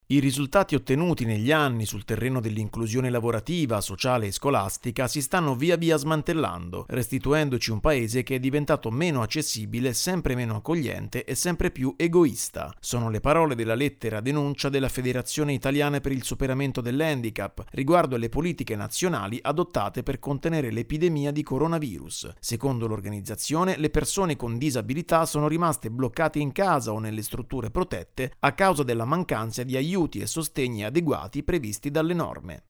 A un anno di distanza dallo scoppio della pandemia la Fish traccia un bilancio delle politiche adottate per le persone con disabilità attraverso una lettera-denuncia. Il servizio